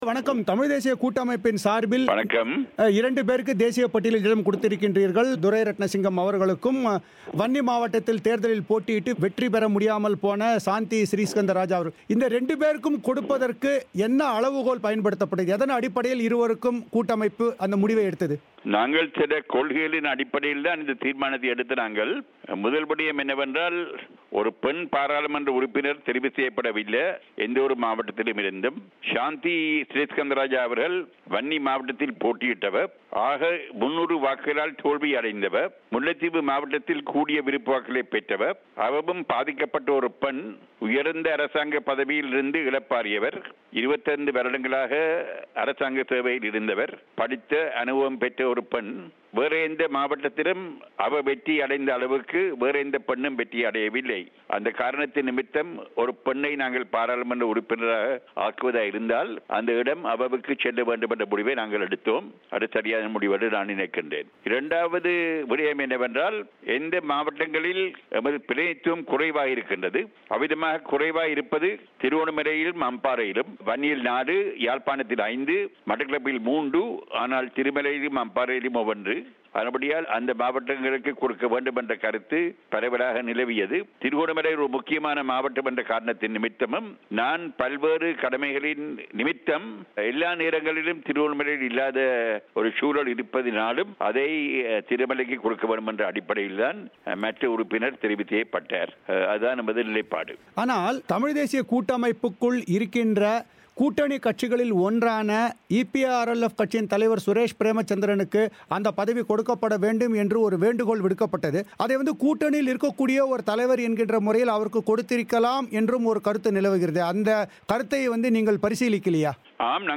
இது குறித்து சம்பந்தர் பிபிசி தமிழோசைக்கு அளித்த பிரத்யேகப் பேட்டி.